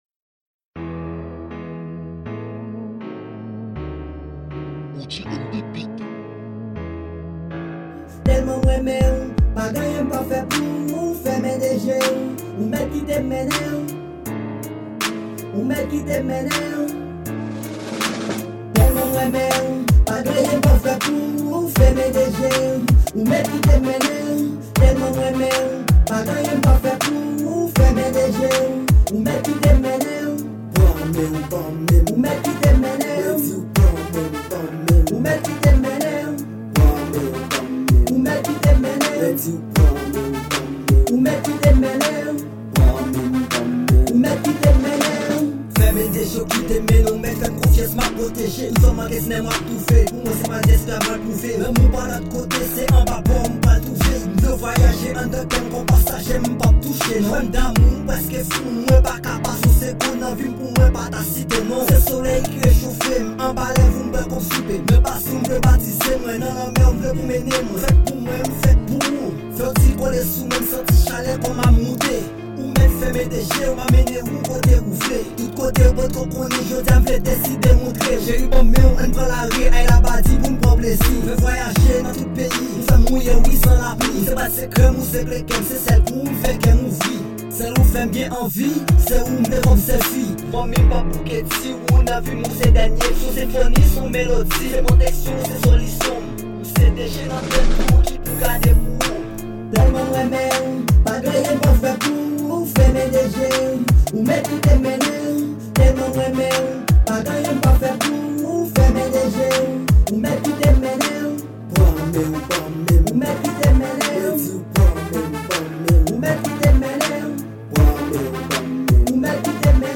Genre: RAAP.